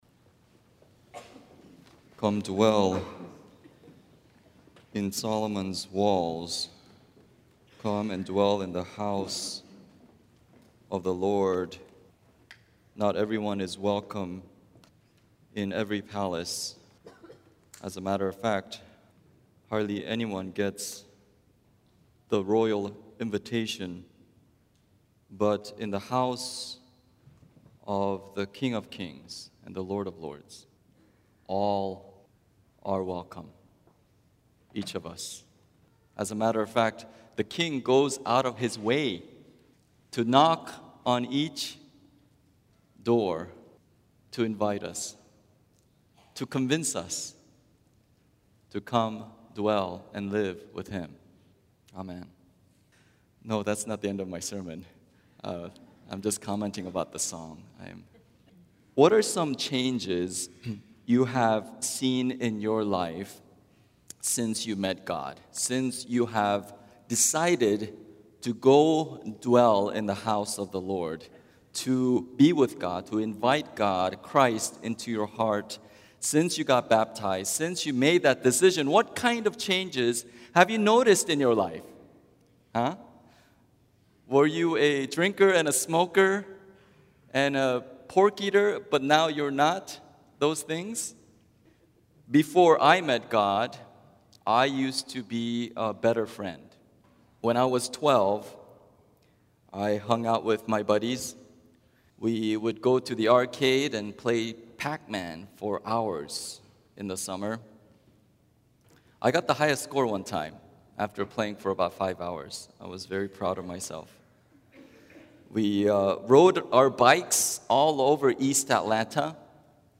SERMONS 2013